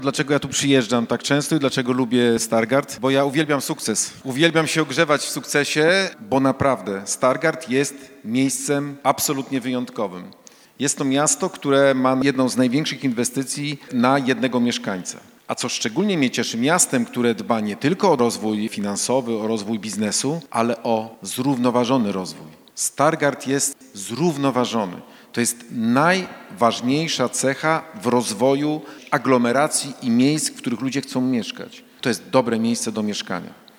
Specjalnym gościem piątkowego wydarzenia był Wojewoda Zachodniopomorski Adam Rudawski, który, jak wynika z jego wypowiedzi, jest oczarowany miastem nad Iną..